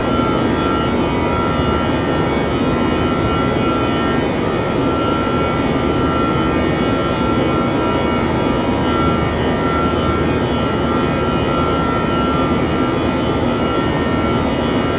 cfm-buzz.wav